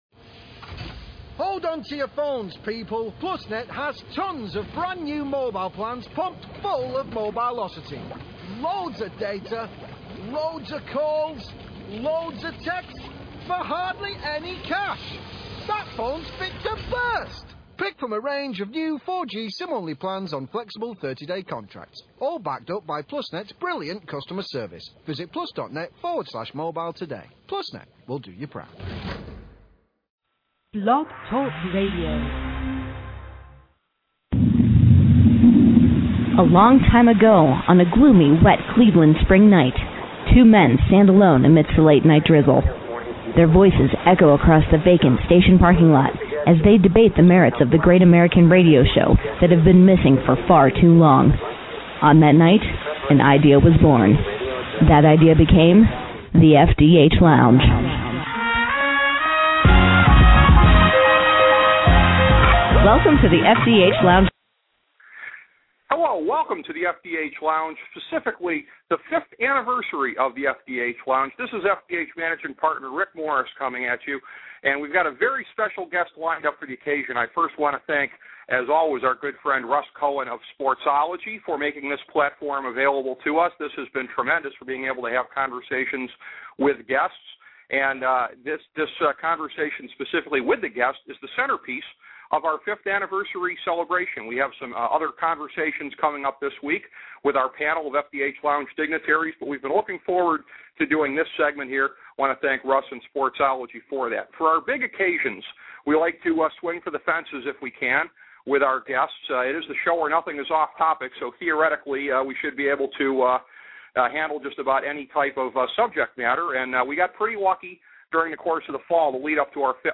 A conversation with 5-time Grammy winner Christopher Cross